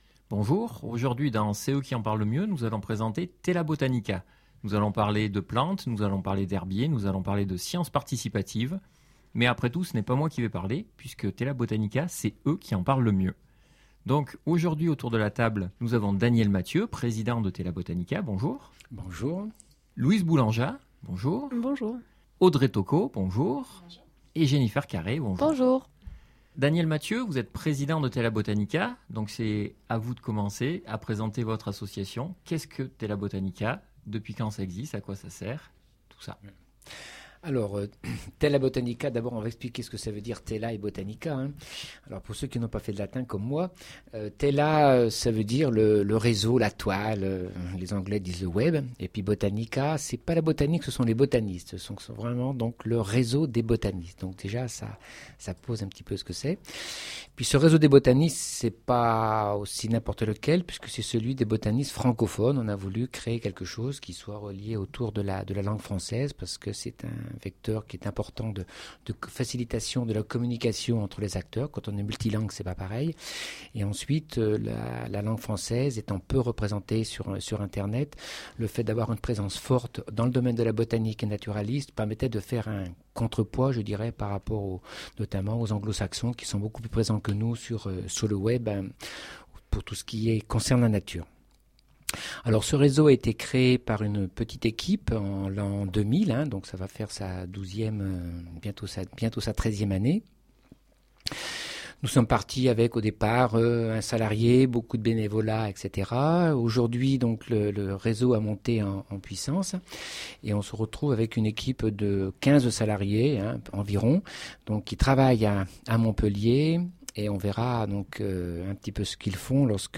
RADIO / Terra One, radio locale consacrée à l’environnement à Montpellier, parle du réseau Tela Botanica !